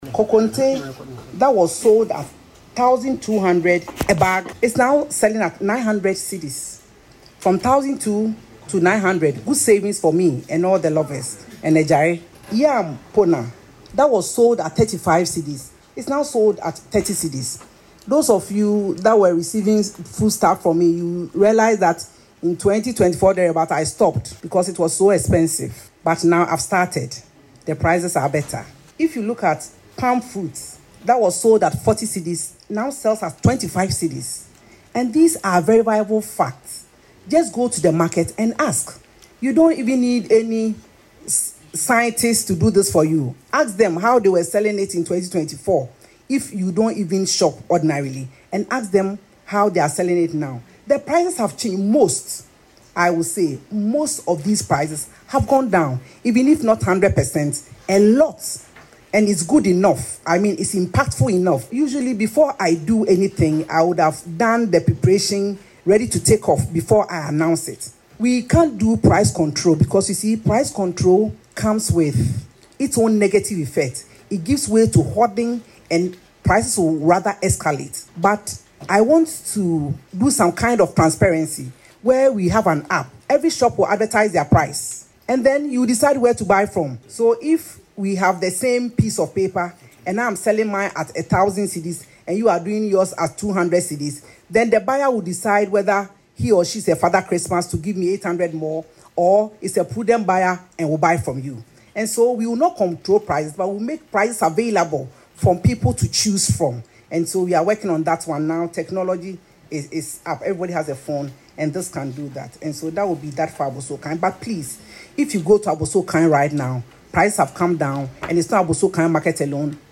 Speaking at the Government Accountability Series on Wednesday, 21st January, the Minister cited food items such as konkonte, yam, and palm fruits as clear examples of commodities whose prices have seen noticeable reductions in recent times.
Listen to Elizabeth Ofosu-Agyare explain the price drop in the audio below:👇